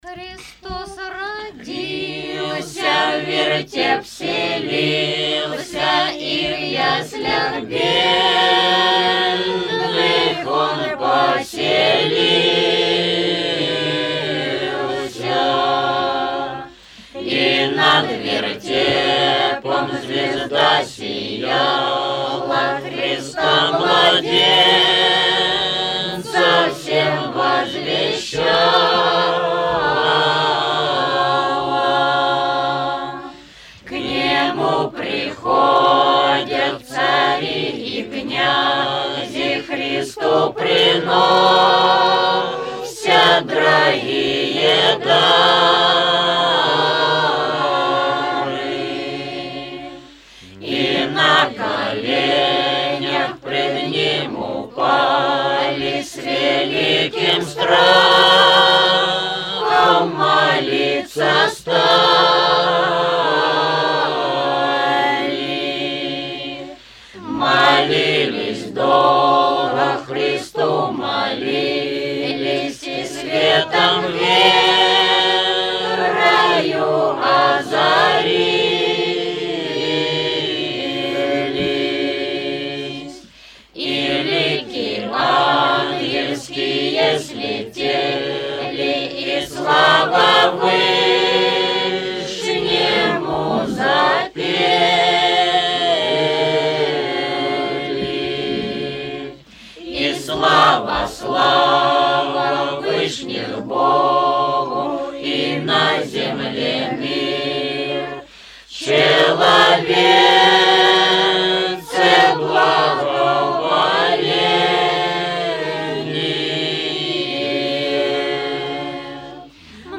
Hor-obshhiny-Fedorovczev-Hristos-rodilsya-v-vertep-vselilsya.-Rozhdestvenskij-duhovn.mp3